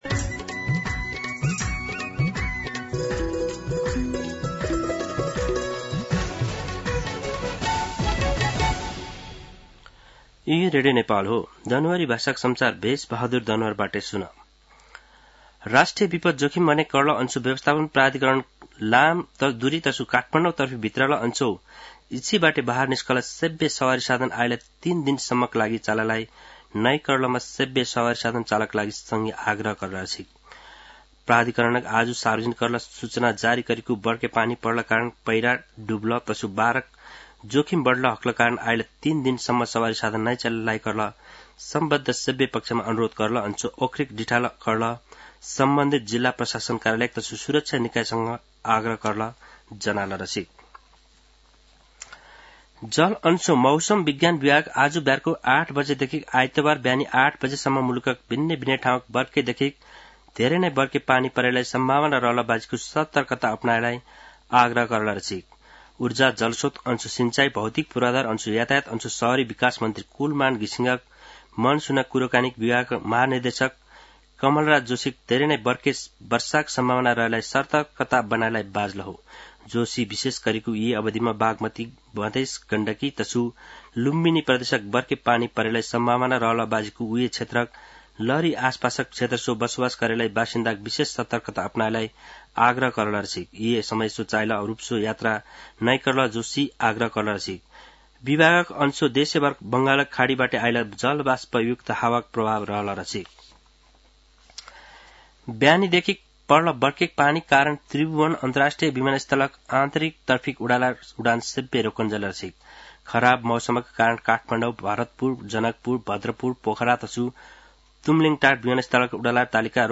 दनुवार भाषामा समाचार : १८ असोज , २०८२
Danuwar-NEWS-06-18.mp3